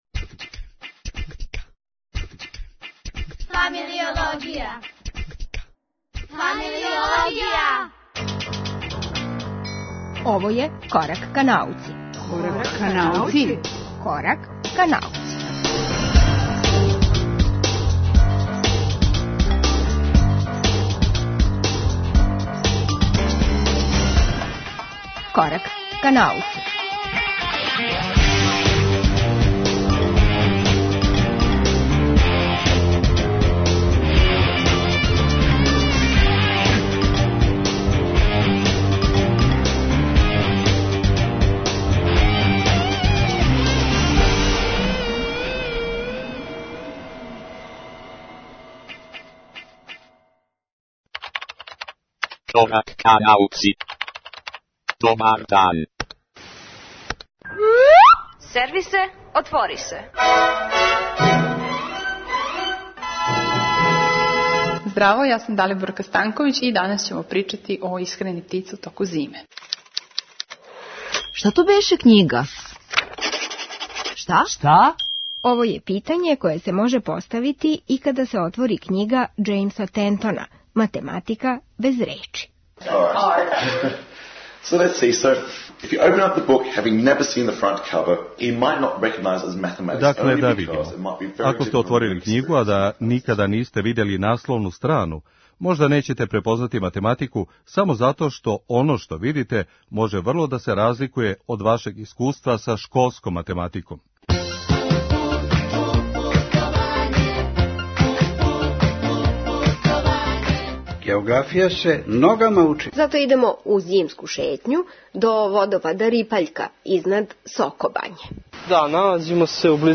Уствари, Математика без речи књига је Џејмса Тентона, птице су оне које храбро подносе хладноћу и оскудно се хране понекад и на прозорским даскама, а зимско путовање звучни је запис из сокобањског краја.